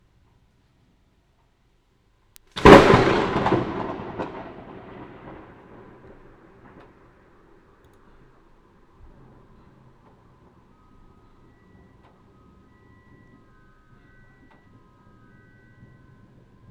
Получилось записать на ленточник очень близкую грозу на фоне начинающегося дождя,как раз как в задании именно "над головой" молния ударила максимум в 50 метрах... Вложения Гроза4 укорочена.wav Гроза4 укорочена.wav 6,1 MB · Просмотры: 200